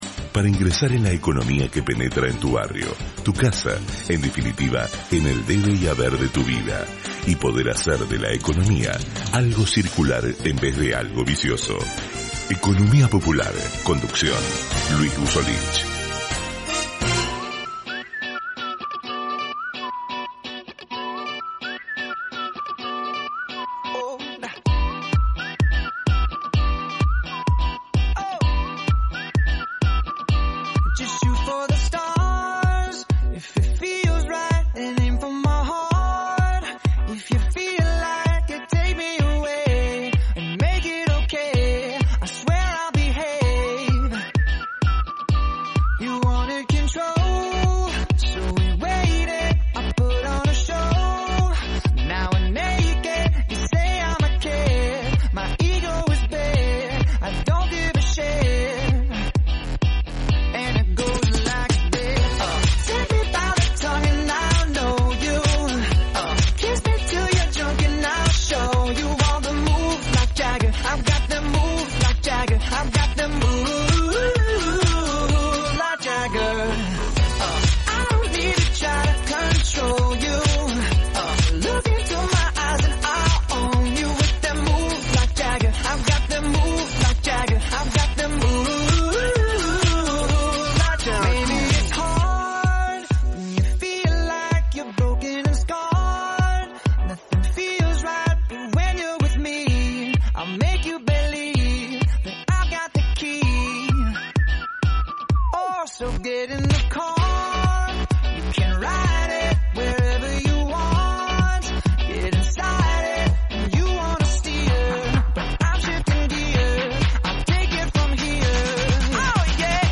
Además, entrevistamos